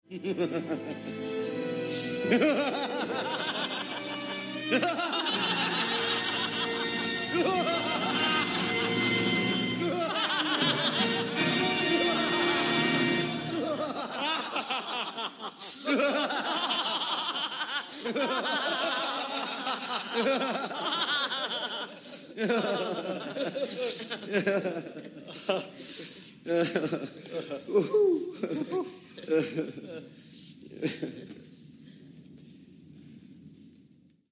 Mwahahaha - Evil Laugh